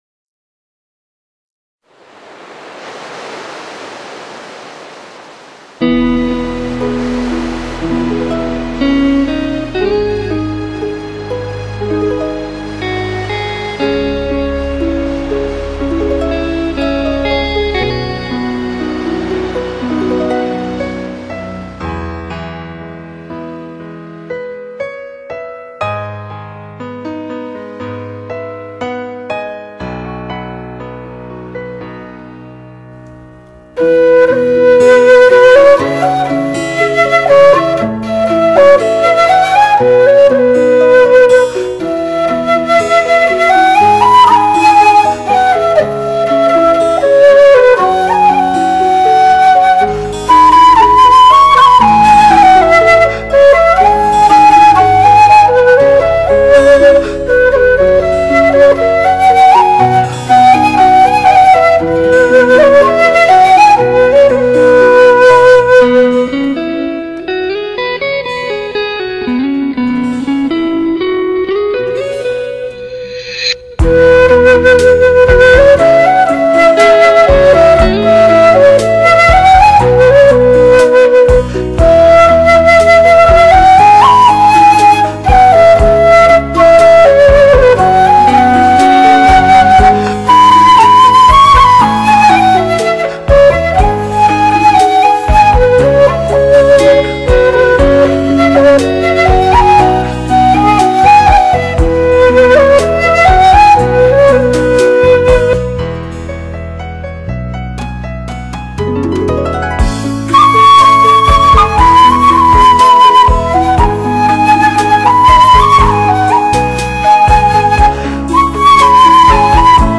经过了几次转换,自然有些损耗,
但音质还是很不错.大家可以试听一下。